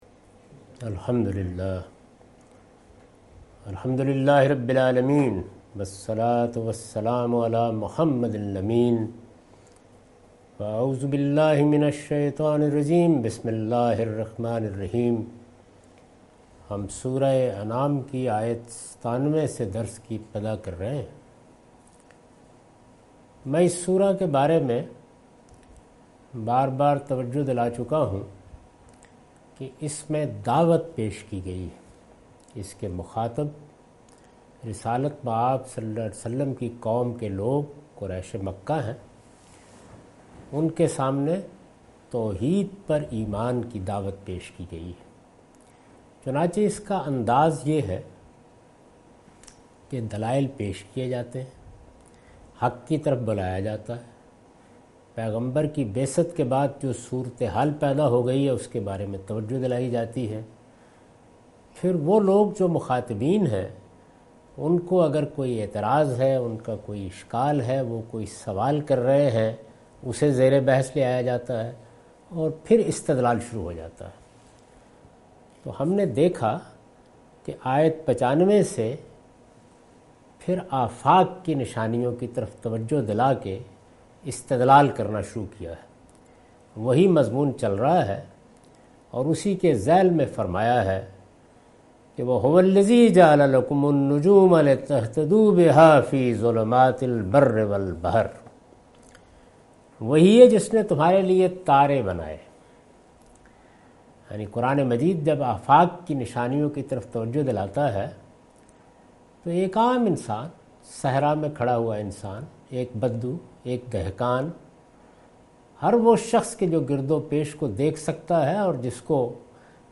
Surah Al-Anam - A lecture of Tafseer-ul-Quran – Al-Bayan by Javed Ahmad Ghamidi. Commentary and explanation of verse 97-99